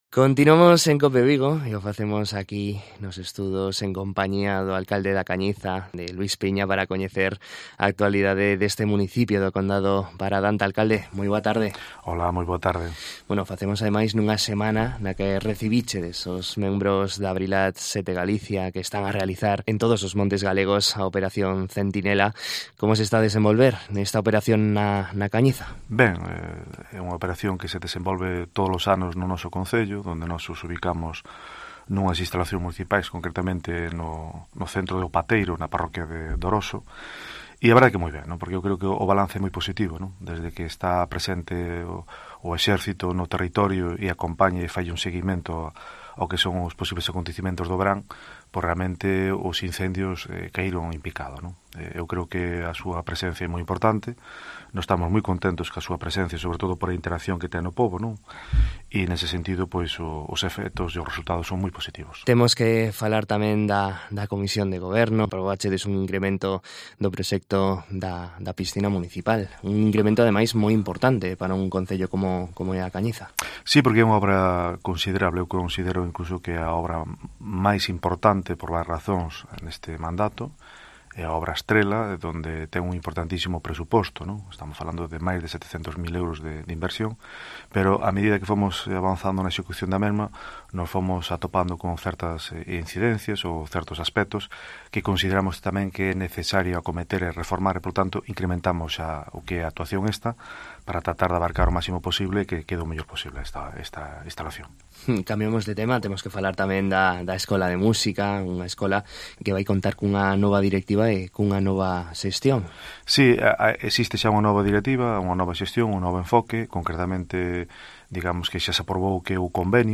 Coñecemos a actualidade de A Cañiza da man do seu alcalde